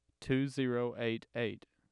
2. Spectrogram and AIF tracks for speech utterance “